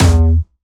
Jumpstyle Kick 1
12 E2.wav